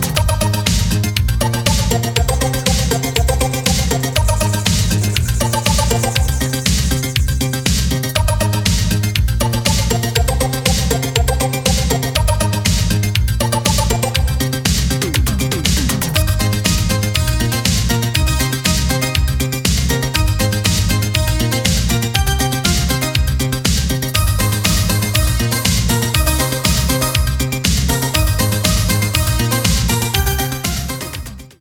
ретро рингтоны